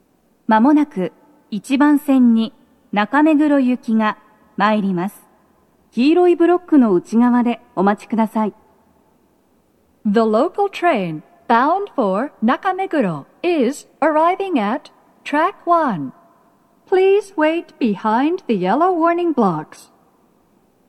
スピーカー種類 BOSE天井型
鳴動は、やや遅めです。
１番線 中目黒方面 接近放送 【女声